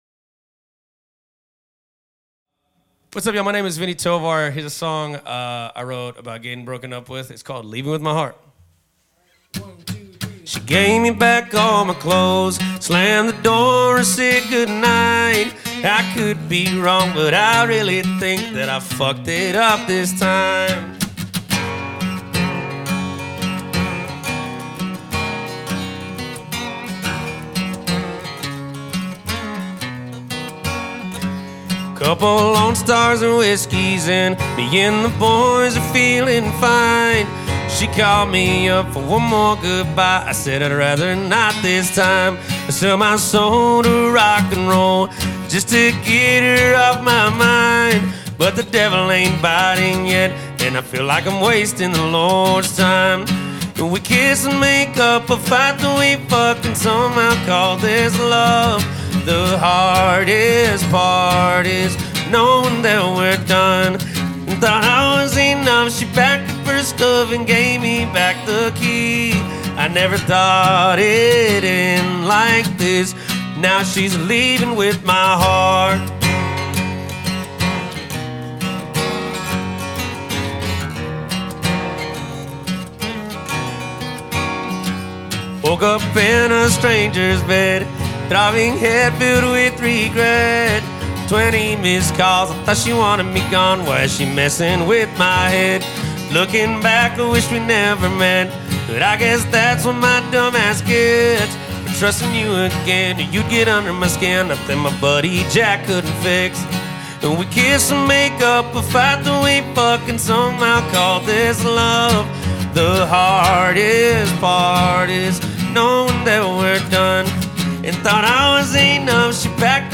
and a true ambassador for the country music genre.